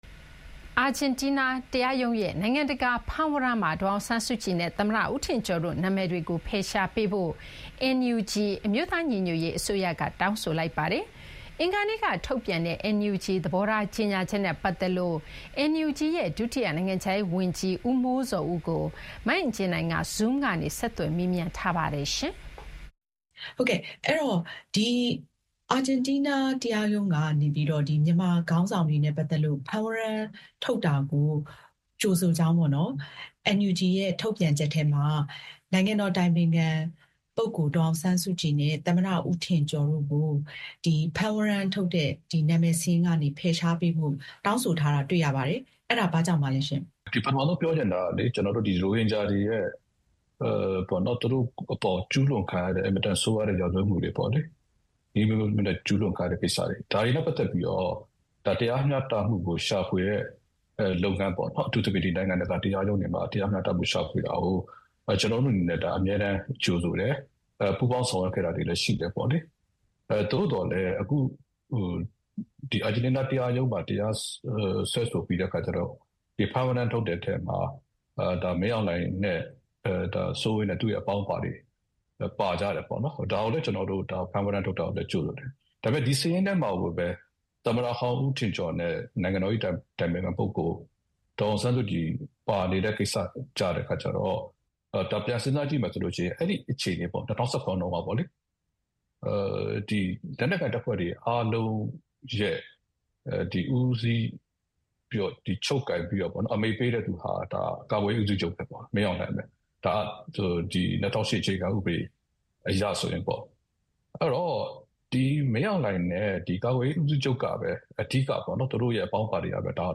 မြန်မာစစ်ခေါင်းဆောင်တွေနဲ့ အရပ်ဖက်ခေါင်းဆောင်တွေပါတဲ့ အာဂျင်တီးနားတရားတရားရုံးရဲ့ နိုင်ငံတကာဖမ်းဝရမ်းမှာ ဒေါ်အောင်ဆန်းစုကြည်နဲ့ သမ္မတ ဦးထင်ကျော်တို့ နာမည်တွေကို ဖယ်ရှားပေးဖို့ NUG အမျိုးသားညီညွတ်ရေးအစိုးရက တောင်းဆိုလိုက်ပါတယ်။ NUG ဒုတိယ နိုင်ငံခြားရေး၀န်ကြီး ဦးမိုးဇော်ဦးကို မေးမြန်းထားပါတယ်။